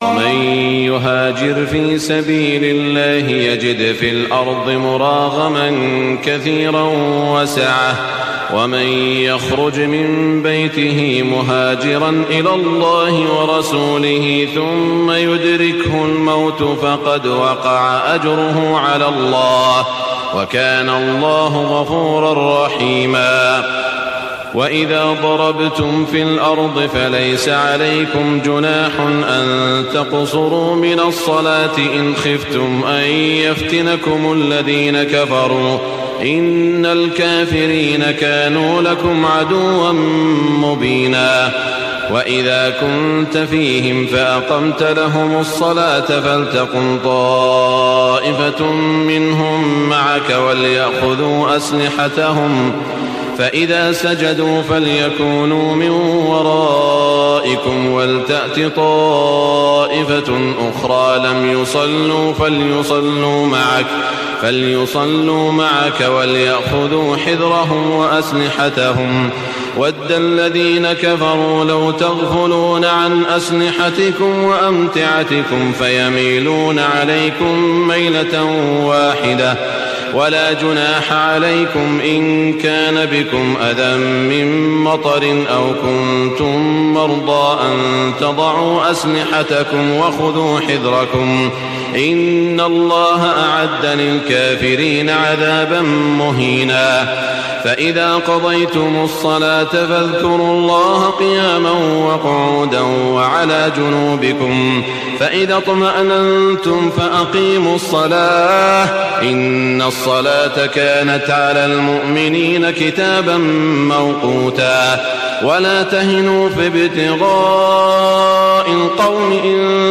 تهجد ليلة 25 رمضان 1423هـ من سورة النساء (100-147) Tahajjud 25 st night Ramadan 1423H from Surah An-Nisaa > تراويح الحرم المكي عام 1423 🕋 > التراويح - تلاوات الحرمين